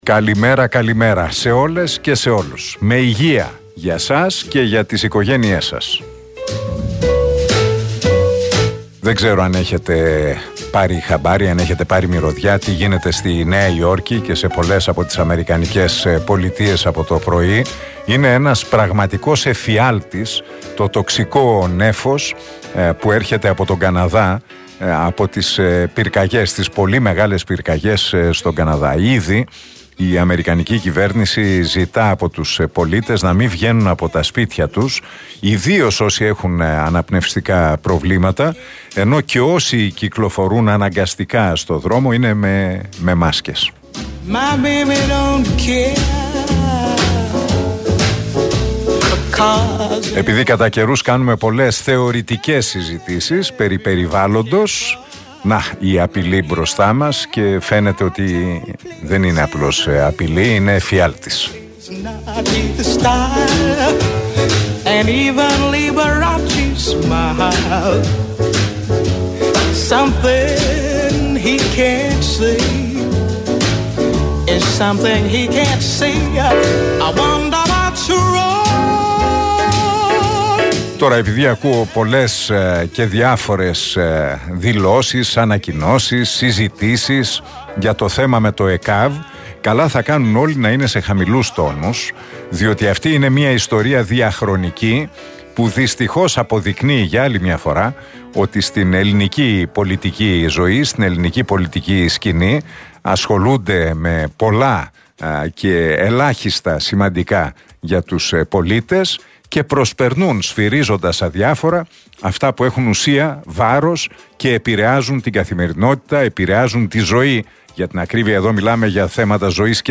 Ακούστε το σχόλιο του Νίκου Χατζηνικολάου στον RealFm 97,8, την Πέμπτη 8 Ιουνίου 2023.